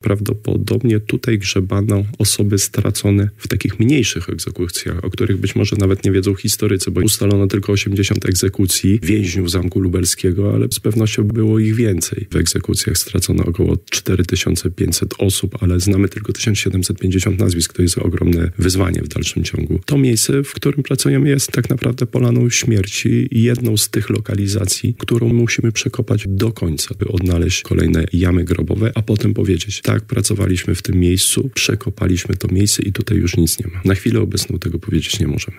w porannej rozmowie Radia Lublin